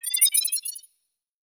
Holographic UI Sounds 79.wav